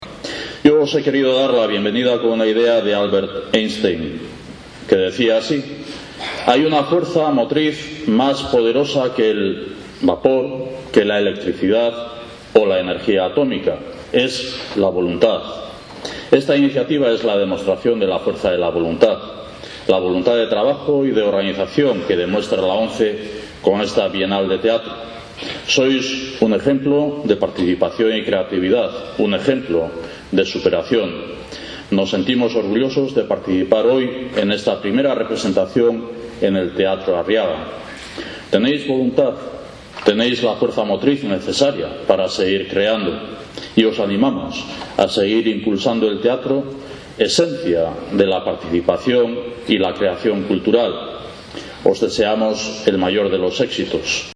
Un Urkullu cercano daba la bienvenida a todos los participantes evocando una cita de Albert Einstein (archivo MP3).